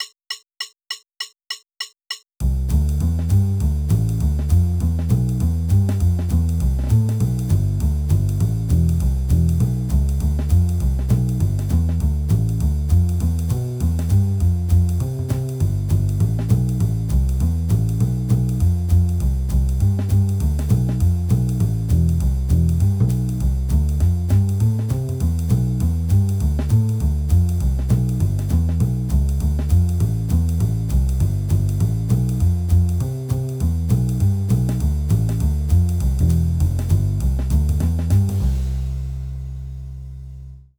次は、カラオケを付けておきますので、あなたも音声にあわせてチャレンジしてみてください。
それでは、カウント8つ聴いてスタート！！